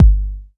909 Kick 4.wav